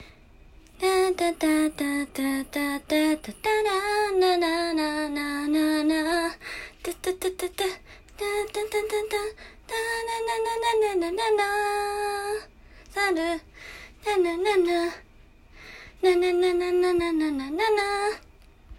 サビ前からサビにかけてのこんなメロディーでした。